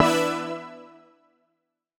Index of /musicradar/future-rave-samples/Poly Chord Hits/Straight
FR_JPEGG[hit]-E.wav